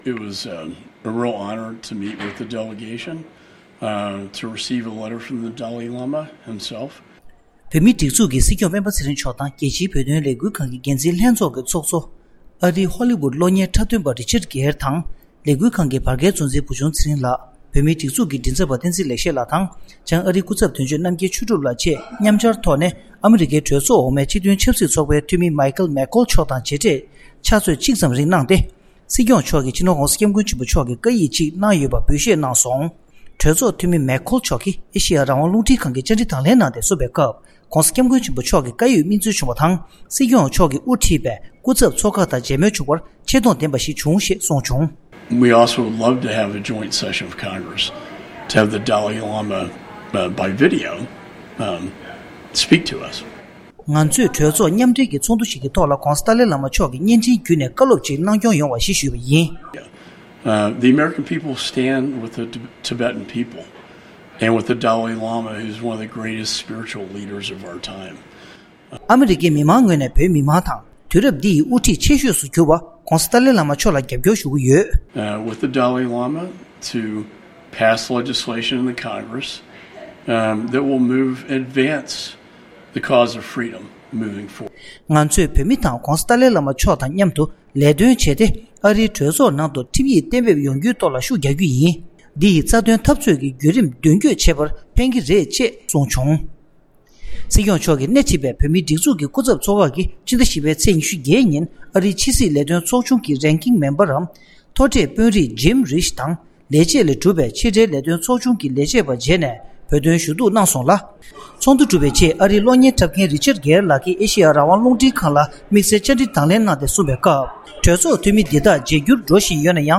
ས་གནས་ནས་བཏང་བའི་གནས་ཚུལ།
སྒྲ་ལྡན་གསར་འགྱུར།